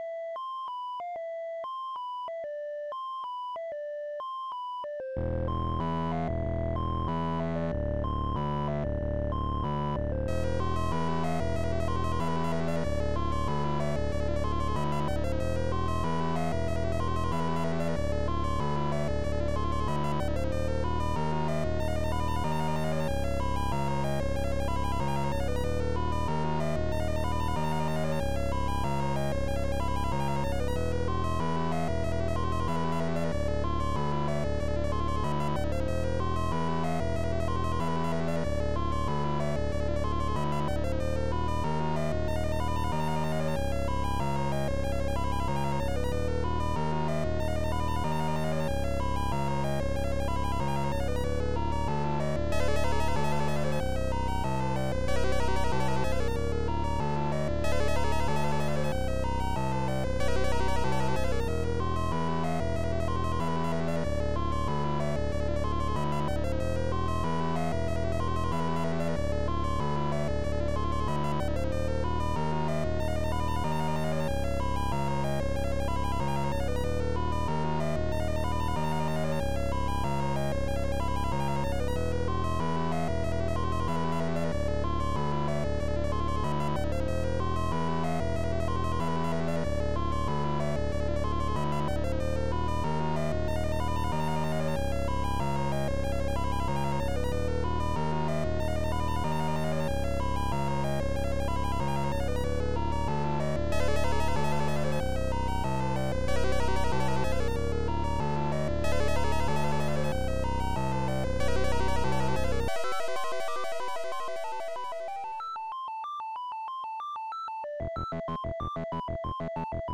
Tracker DSIK DSM-format
Instruments Chip1 Chip2 Chip3